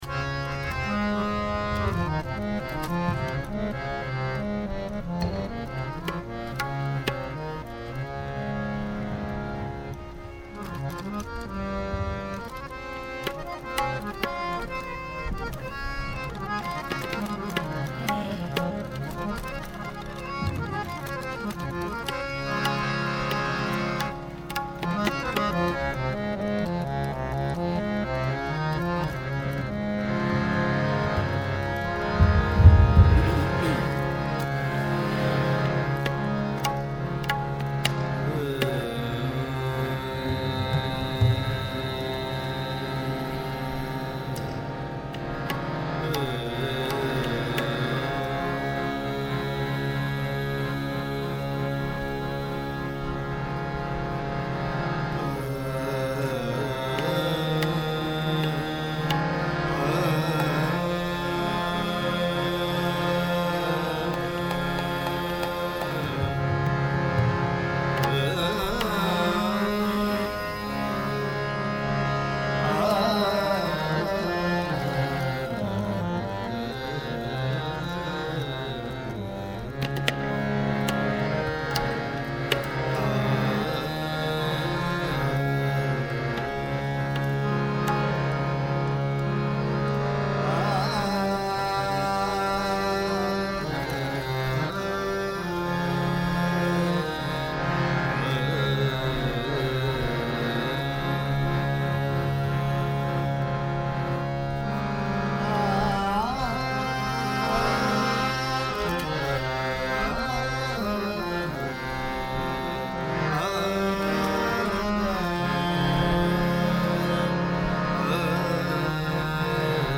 Album:Toronto, Canada - 2007-07-02 - Evening
Genre: Shabad Gurbani Kirtan